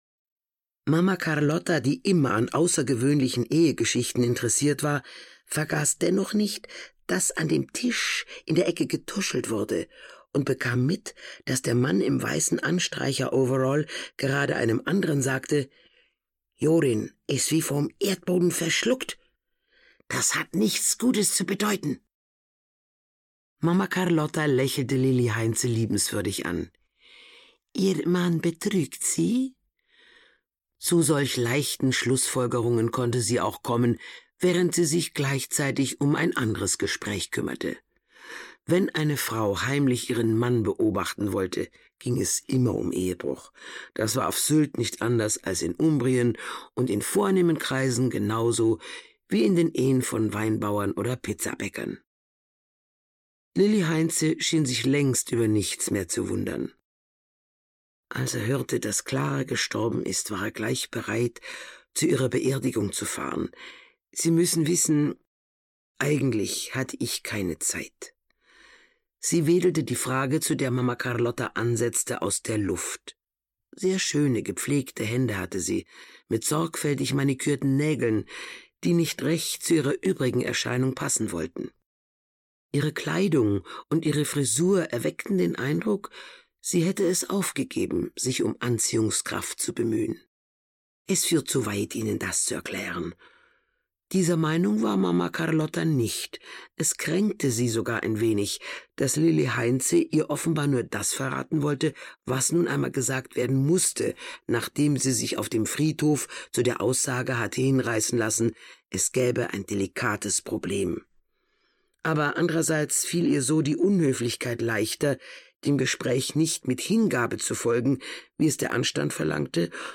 Vogelkoje (Mamma Carlotta 11) - Gisa Pauly - Hörbuch